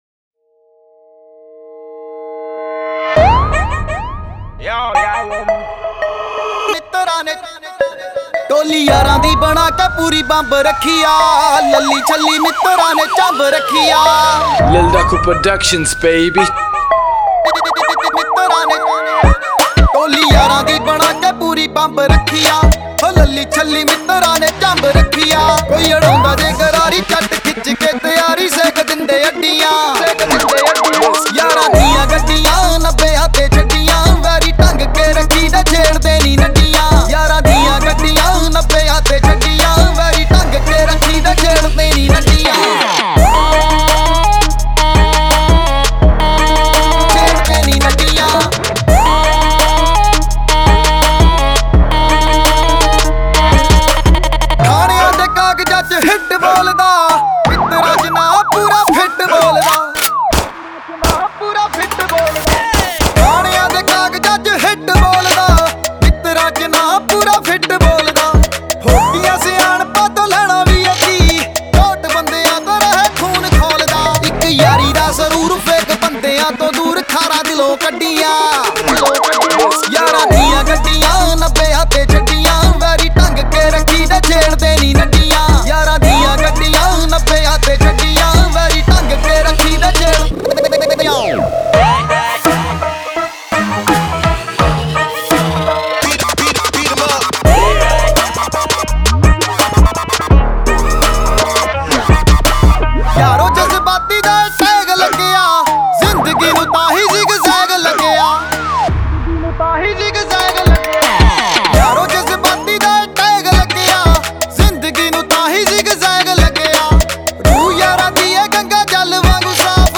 Song Genre : Punjabi Songs